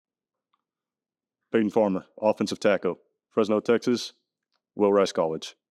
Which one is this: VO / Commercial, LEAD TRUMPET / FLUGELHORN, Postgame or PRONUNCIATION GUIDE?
PRONUNCIATION GUIDE